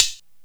Hat (76).wav